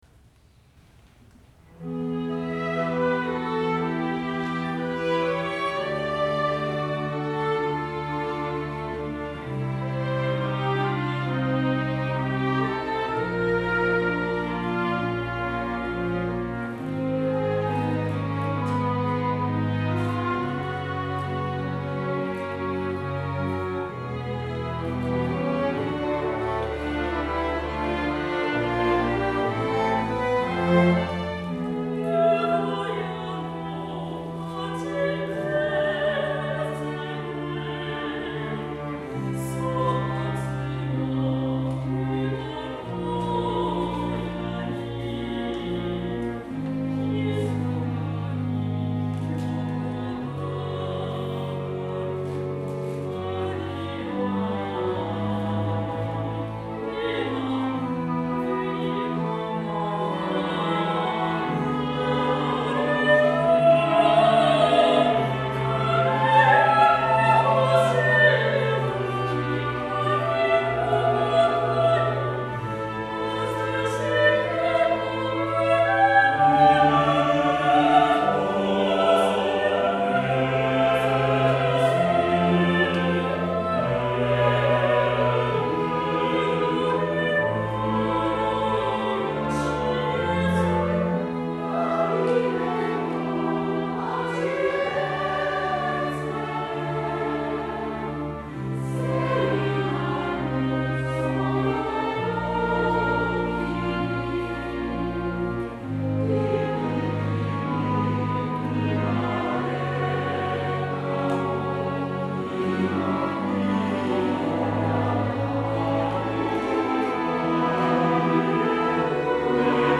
Midi Instrumental ensemble (Fl-Fl-Fl-Fg-Str)